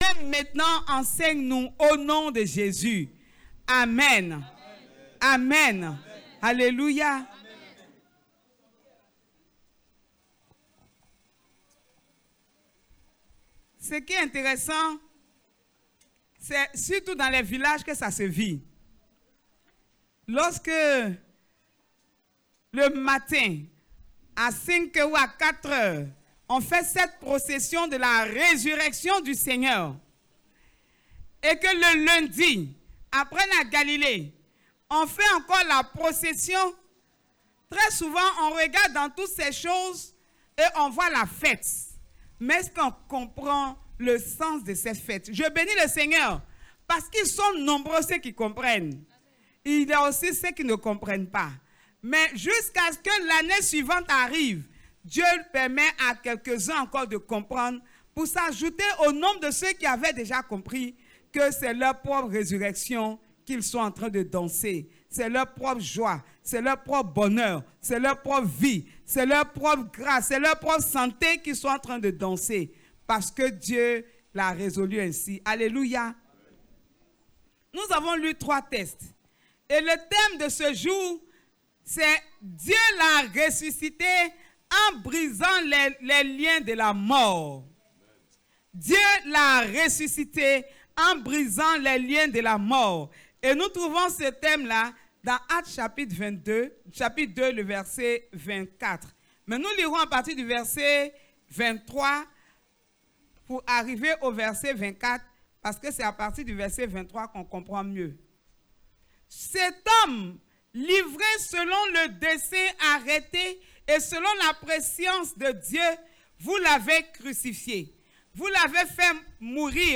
Séries: Fêtes Chrétiennes Passage: Esaie 26: 6-9; Actes 2: 22-32, 36; Luc 24: 1-12;